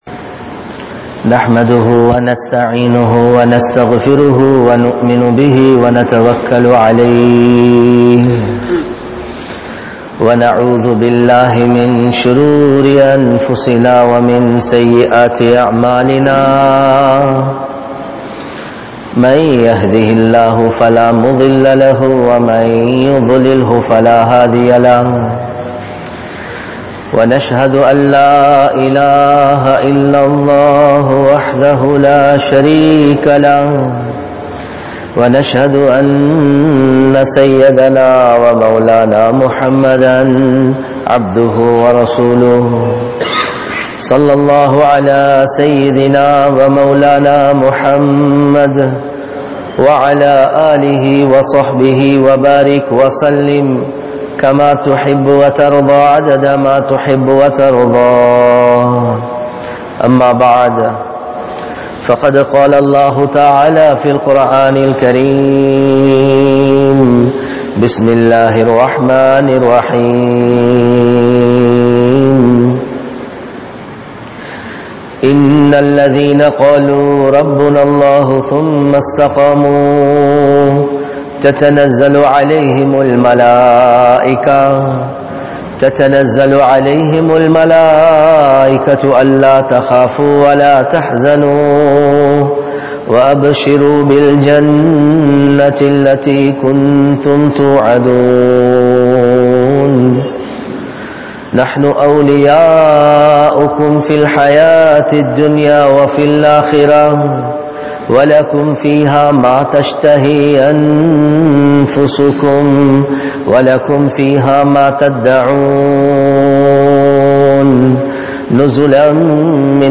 Neengal Muslima? Mumina? (நீங்கள் முஸ்லிமா? முஃமினா?) | Audio Bayans | All Ceylon Muslim Youth Community | Addalaichenai